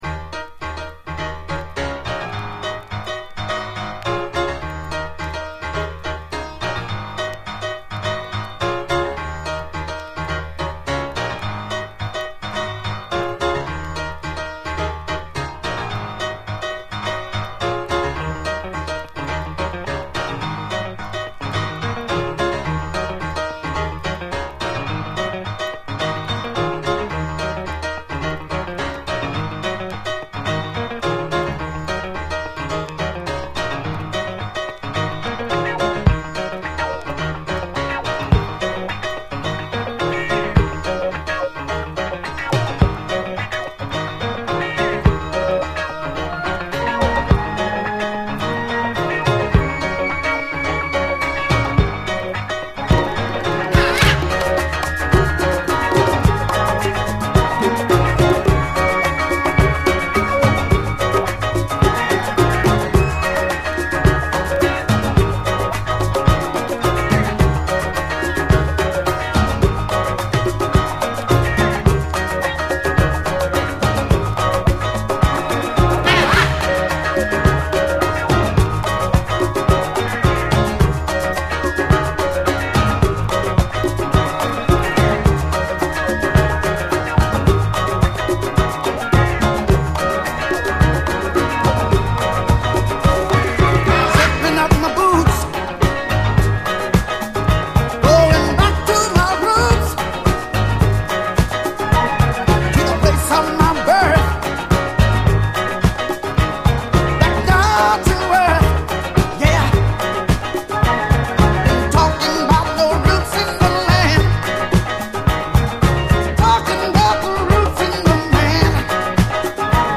サイケデリックなムードに妖気が漂い、鬼気迫るダーク・シスター・ファンク
エレガントなコーラスのスウィート・ソウル